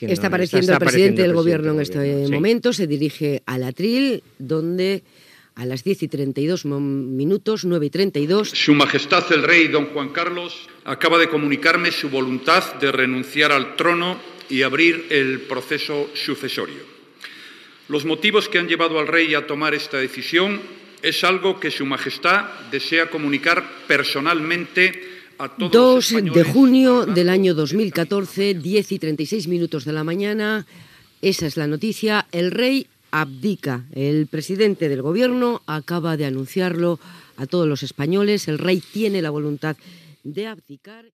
El president del Govern espanyol Mariano Rajoy anuncia que el rei Juan Carlos I ha decidit abdicar.